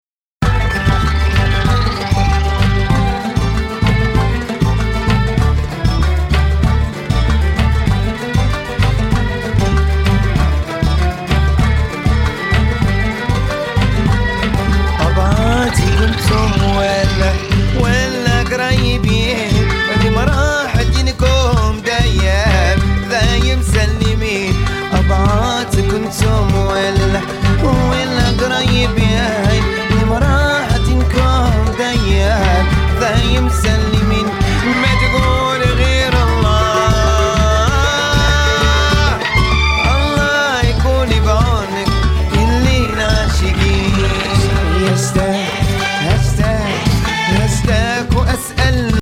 AFRO GROOVEが溢れるモロカン・ファンク大傑作!!